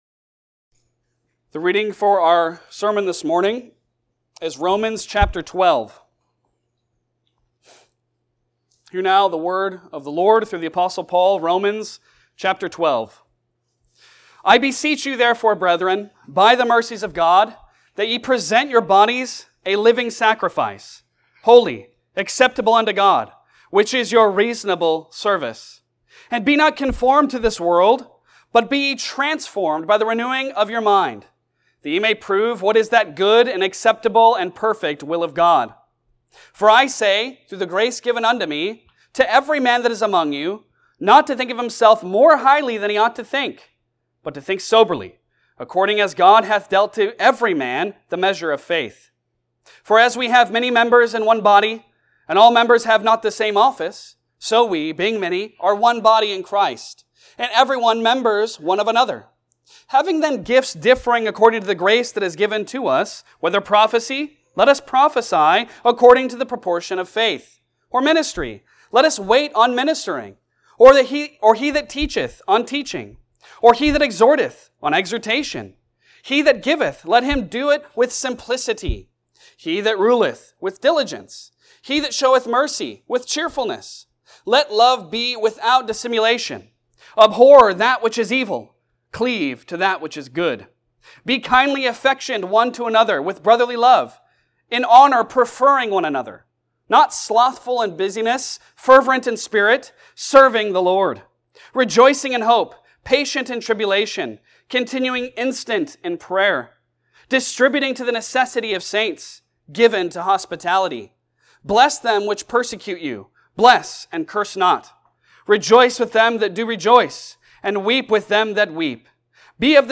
Romans 12 Service Type: Sunday Sermon Download Files Bulletin Topics